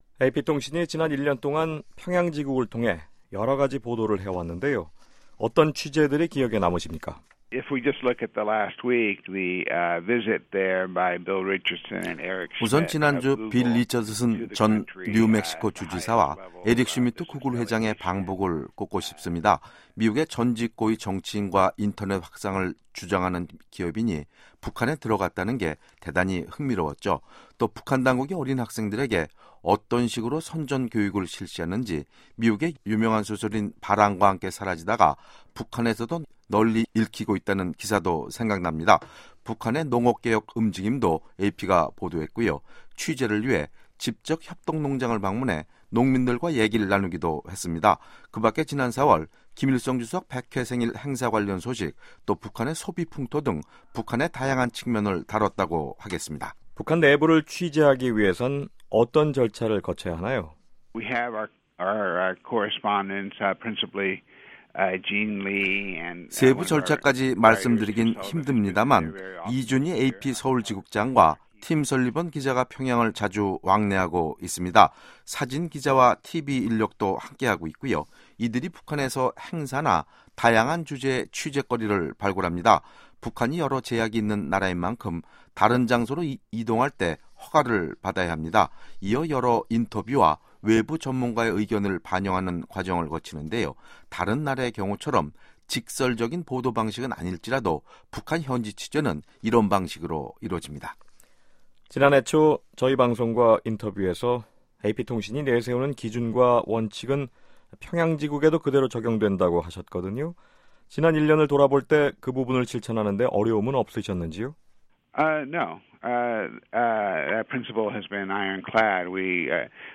[인터뷰]